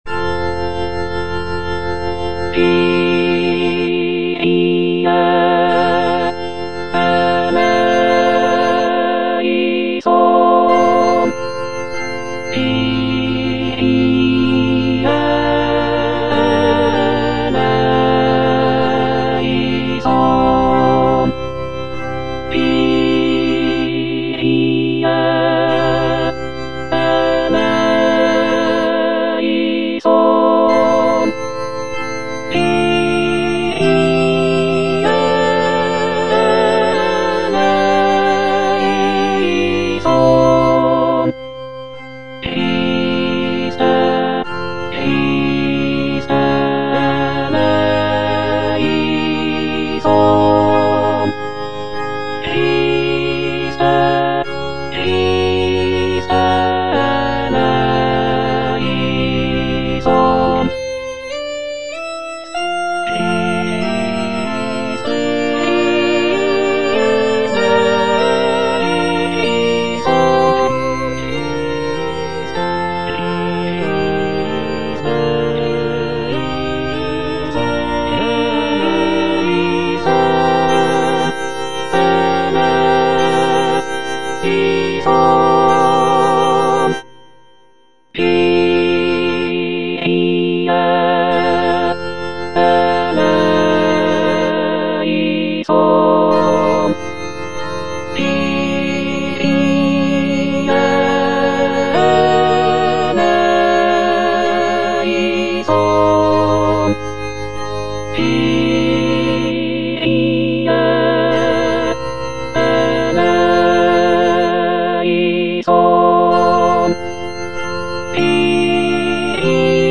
T. DUBOIS - MESSE IN F Kyrie - Alto (Emphasised voice and other voices) Ads stop: auto-stop
"Messe in F" is a choral work composed by Théodore Dubois in the late 19th century. It is a setting of the traditional Catholic Mass text in the key of F major. The piece is known for its lush harmonies, intricate counterpoint, and lyrical melodies.